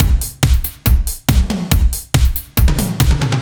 Index of /musicradar/french-house-chillout-samples/140bpm/Beats